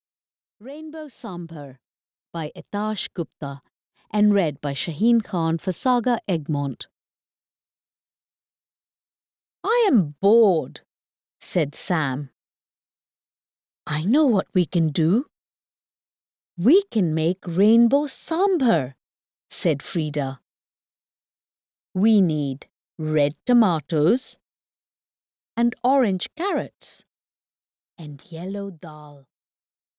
Rainbow Sambhar (EN) audiokniha
Ukázka z knihy